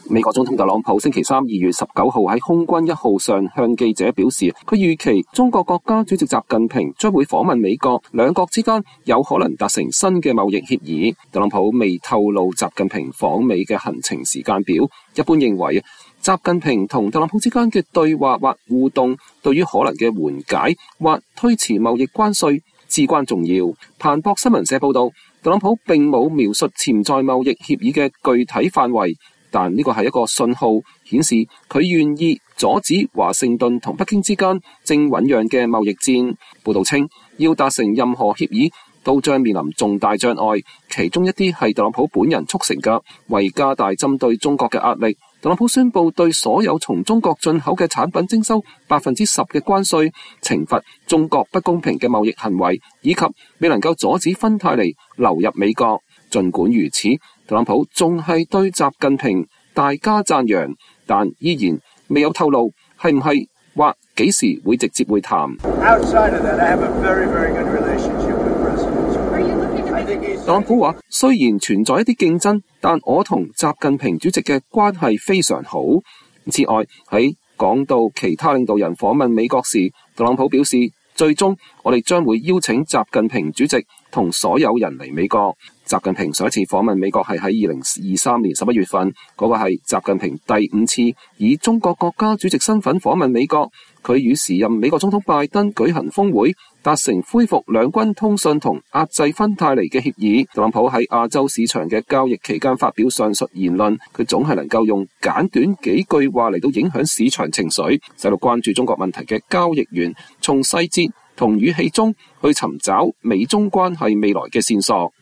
特朗普在空軍一號上向記者表示，他預期中國國家主席習近平將訪美，兩國之間“有可能”達成新的貿易協定。